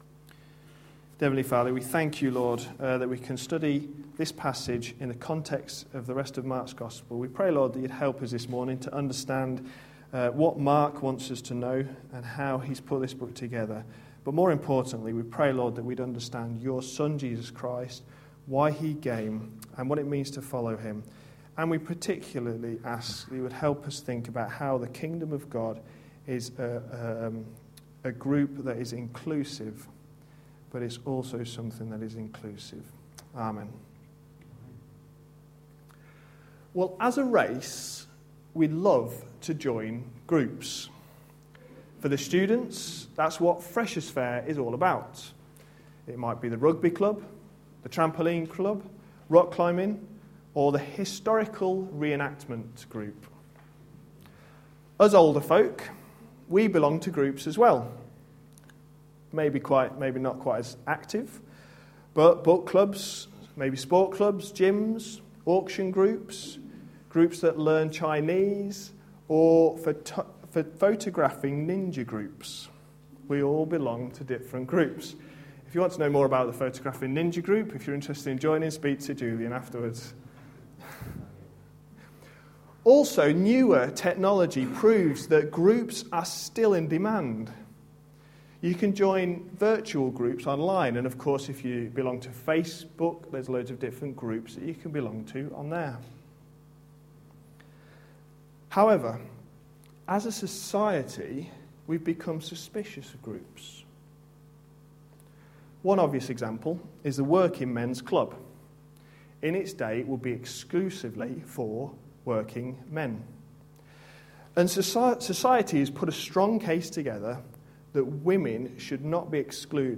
A sermon preached on 27th November, 2011, as part of our Mark series.